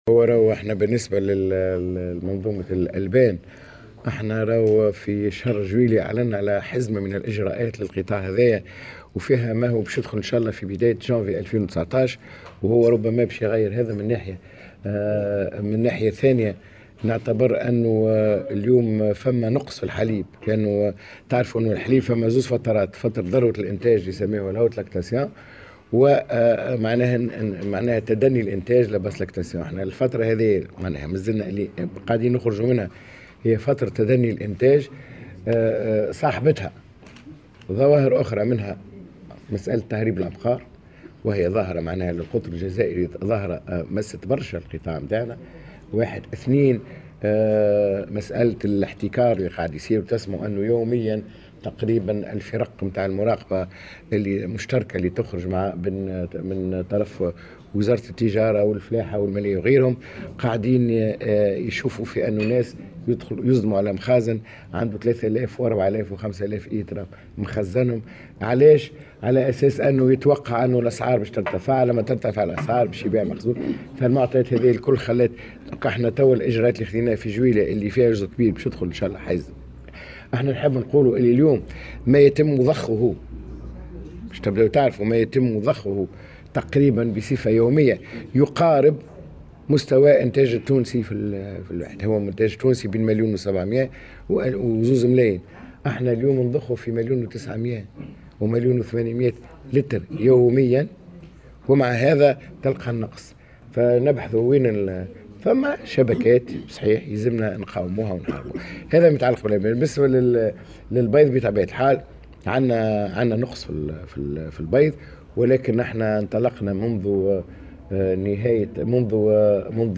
وأكد الوزير في تصريح لمراسل
على هامش حضوره الملتقى الوطني حول منظومة الزراعات المحمية والجيوحرارية في ولاية قابس